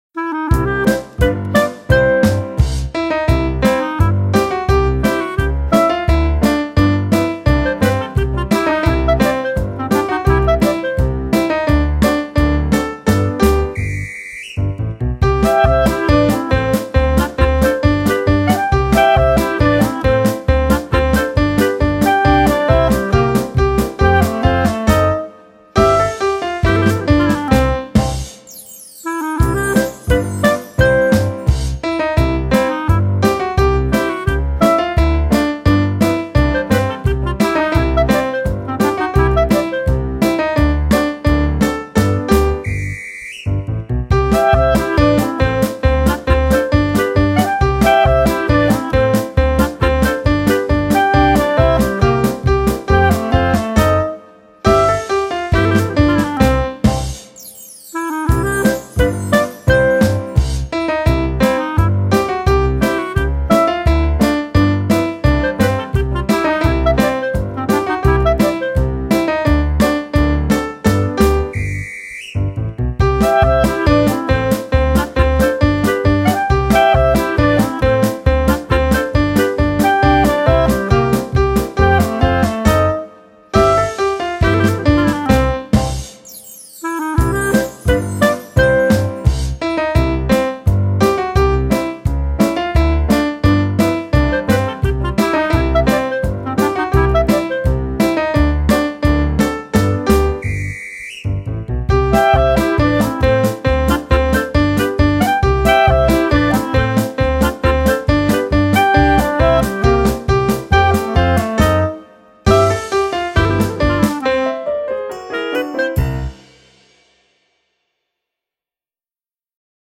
W naszej klasie jak w rodzinie (wersja instrumentalna)
W_naszej_klasie_wersja-instr.wma